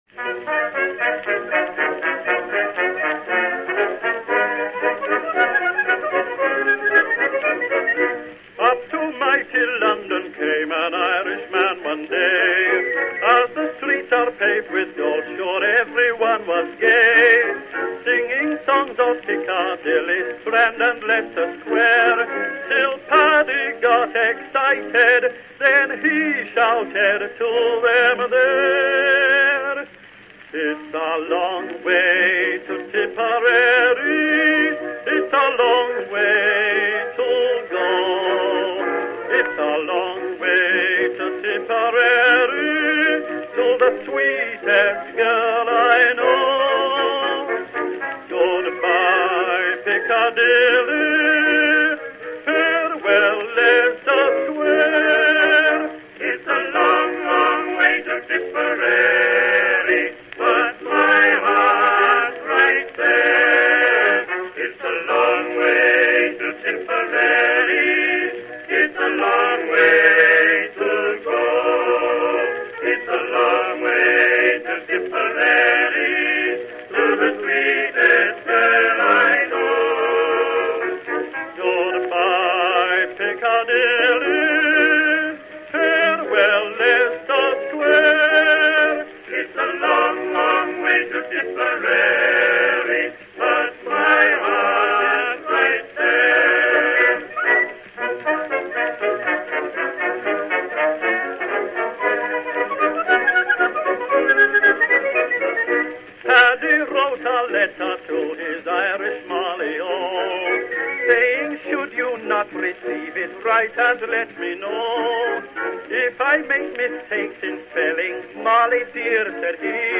Запись сделана очень давно, в январе 1915 года: